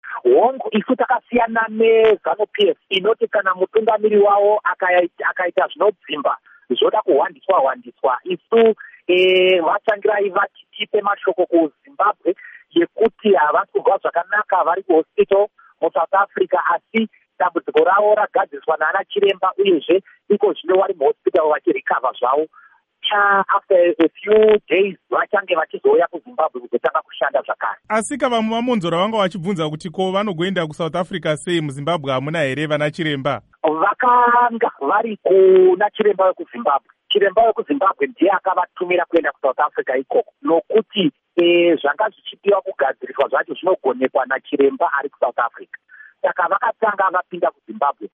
Hurukuro naVaDouglas Mwonzora-t